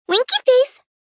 menuhit.mp3